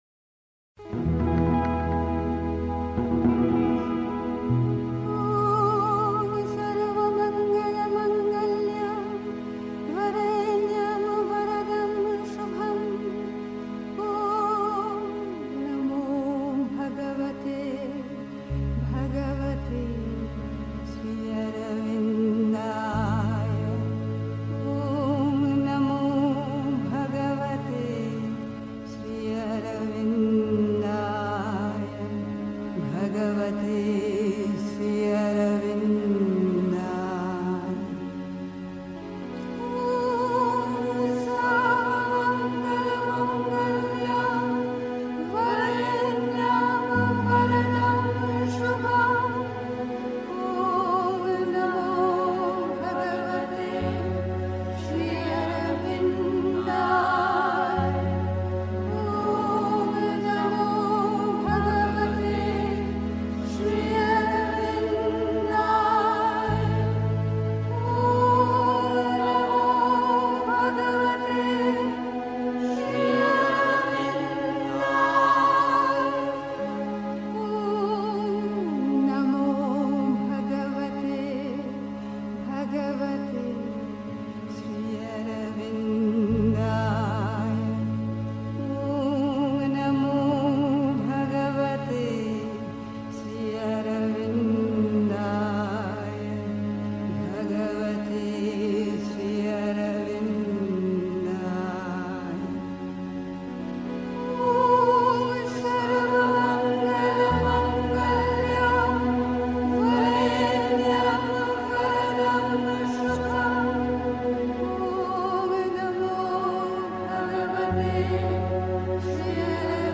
Der Entschluss zum Yoga (Die Mutter, The Sunlit Path) 3. Zwölf Minuten Stille.